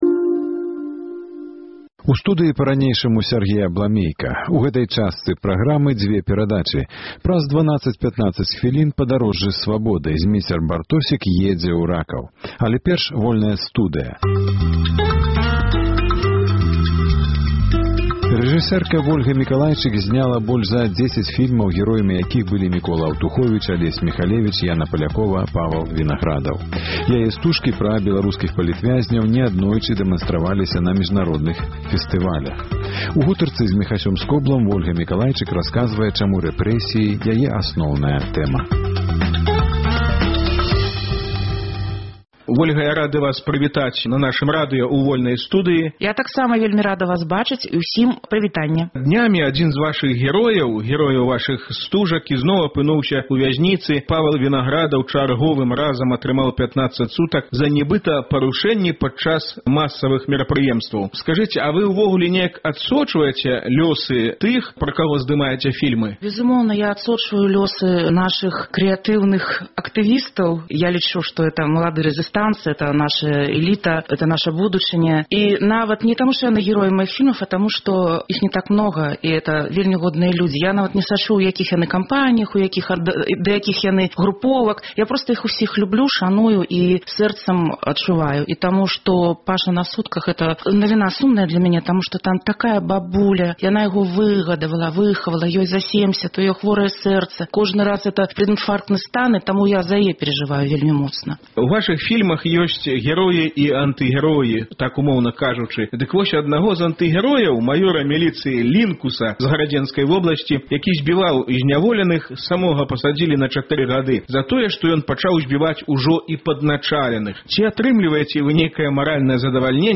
У “Вольнай студыі” рэжысэрка раскажа, чаму палітычныя рэпрэсіі – яе асноўная тэма. Гутарку